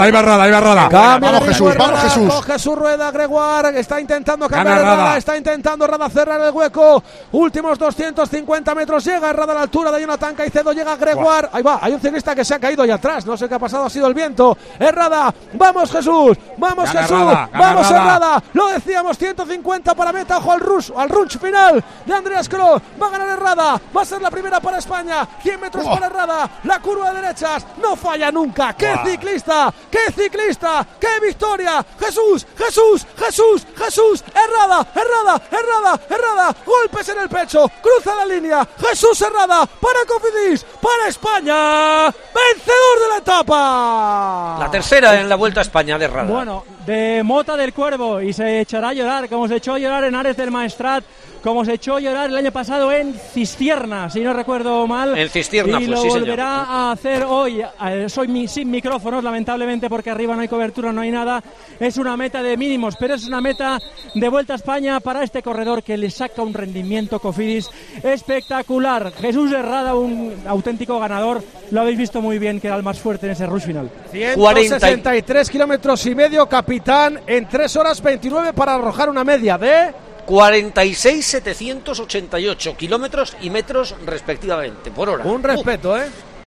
Así hemos narrado en Tiempo de Juego la victoria de Jesús Herrada
Con Paco González, Manolo Lama y Juanma Castaño